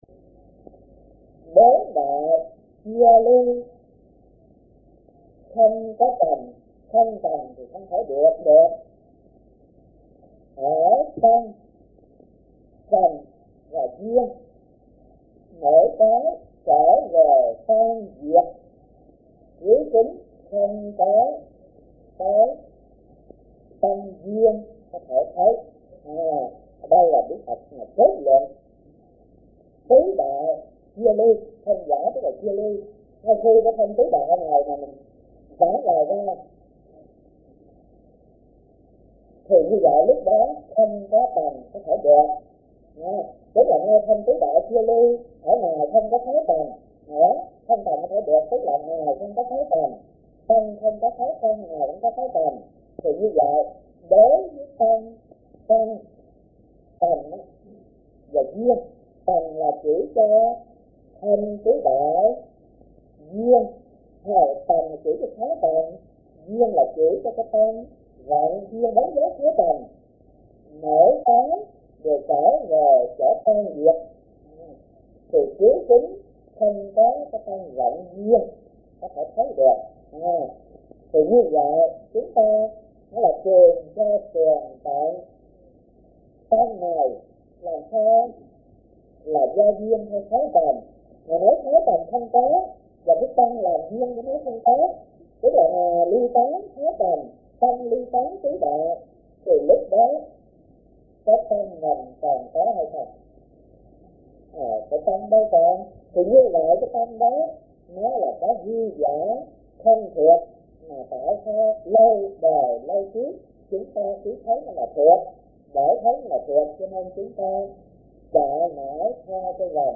Kinh Giảng Kinh Viên Giác - Thích Thanh Từ